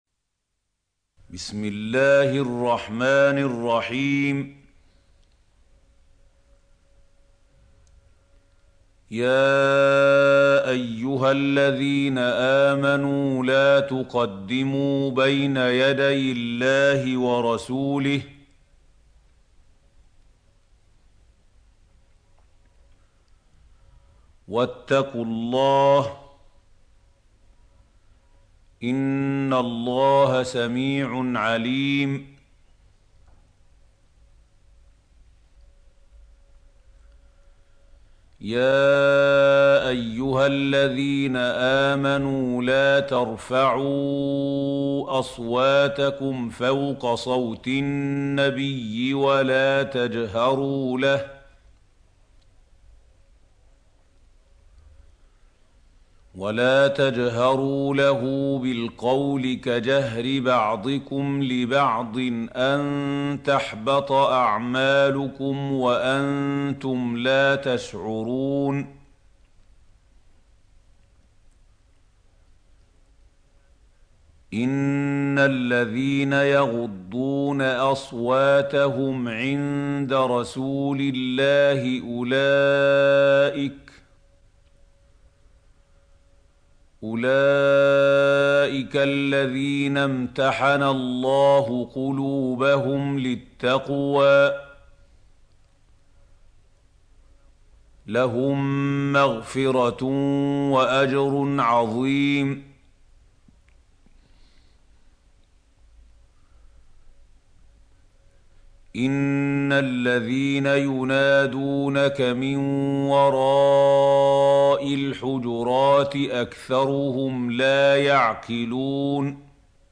سورة الحجرات | القارئ محمود خليل الحصري - المصحف المعلم